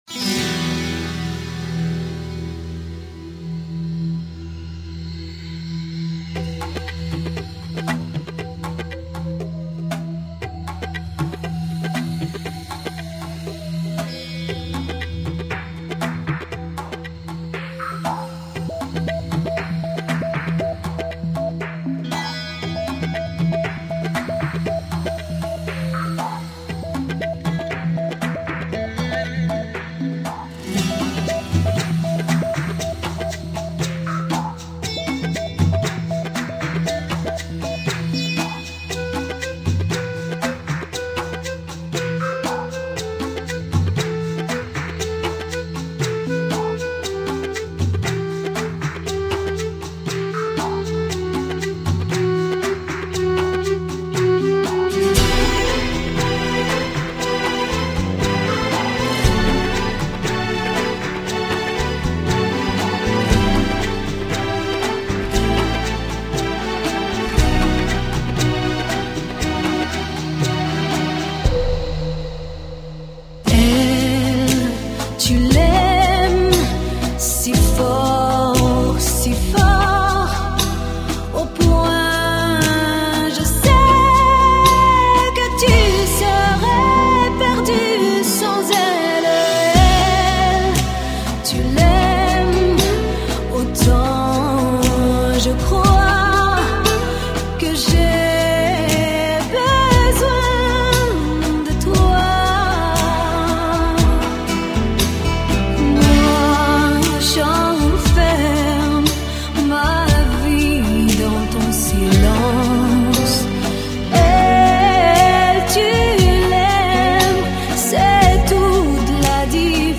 поёт армянка из Канады